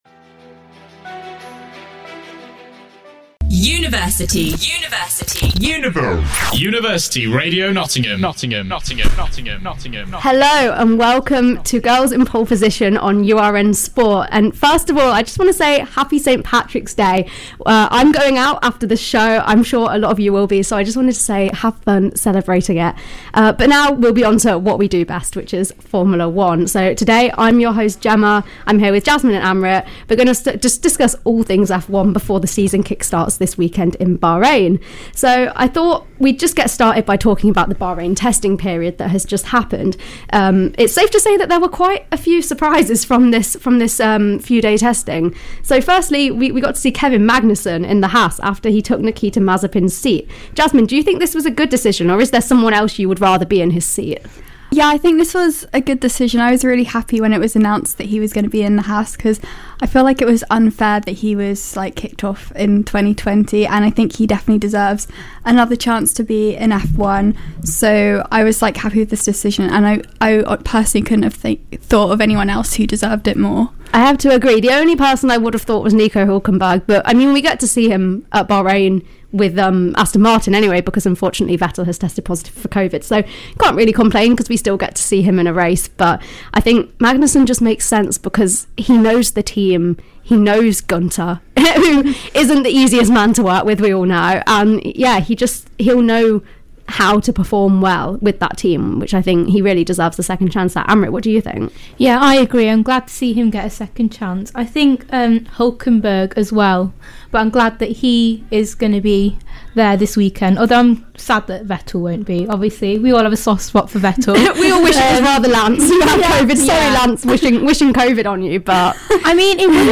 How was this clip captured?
Bahrain predictions and most importantly a complete review of Drive to Survive. Another fantastic live show podcasted just for you, the listeners!